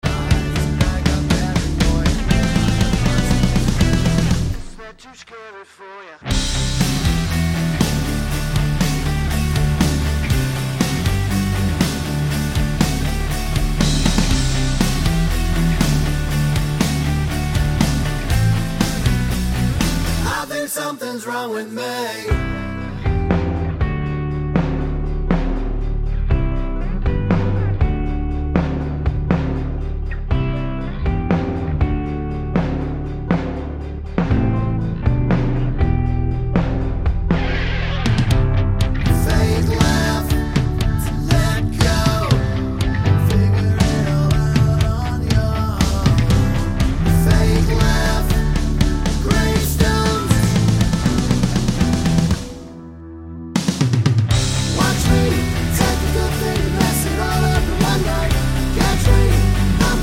Clean R'n'B / Hip Hop 2:50 Buy £1.50